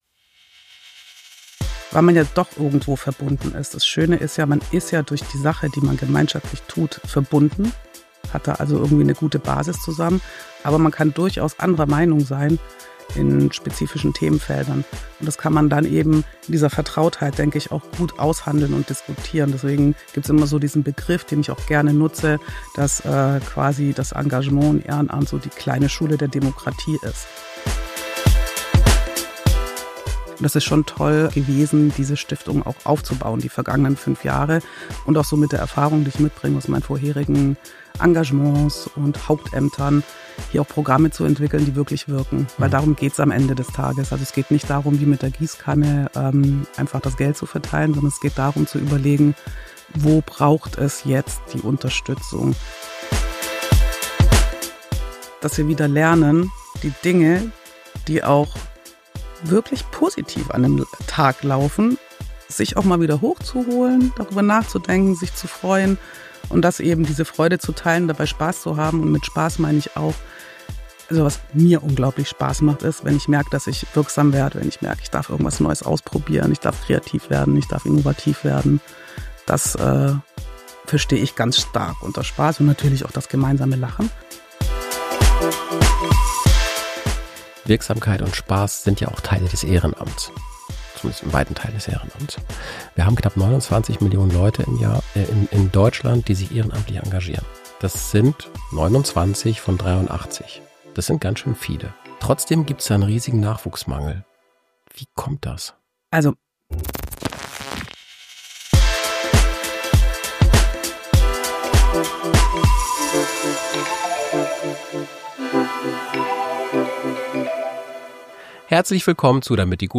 Ein Gespräch über Haltung, Teilhabe – und darüber, warum Demokratie mehr ist als Wählen: nämlich Mitmachen.